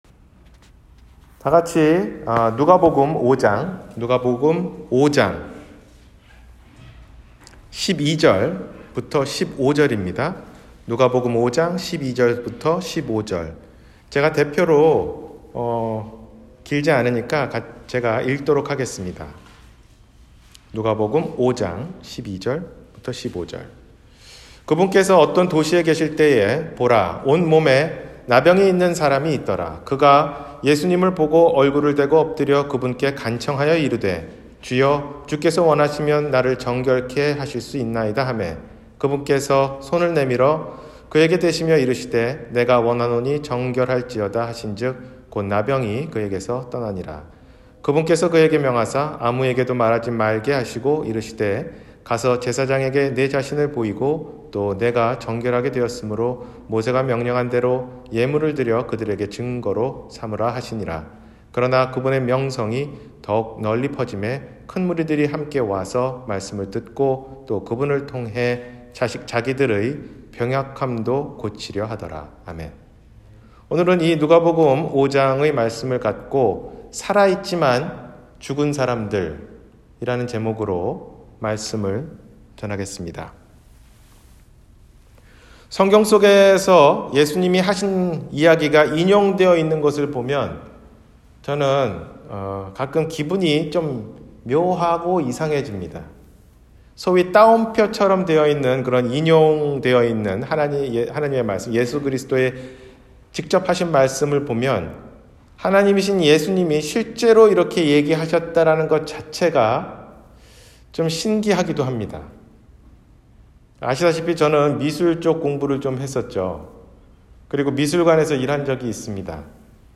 살아있지만 죽은 사람 – 주일설교